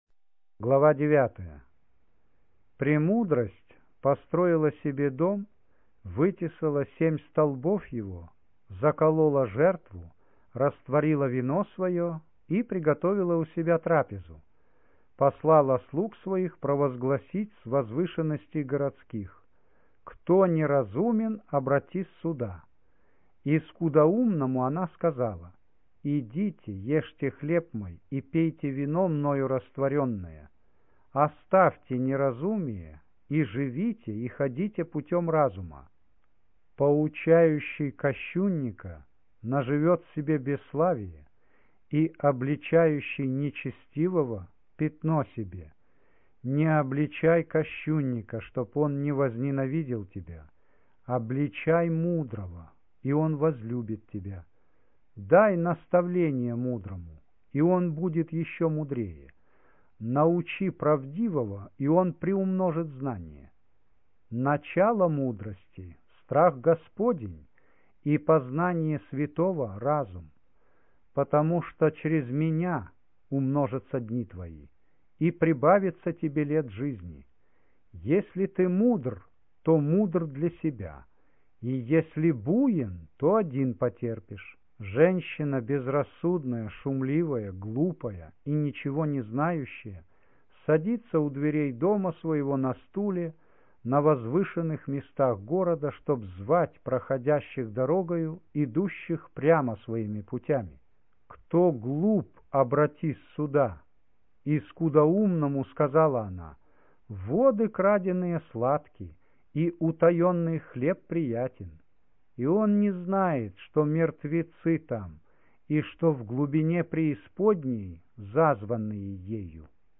Аудио Библия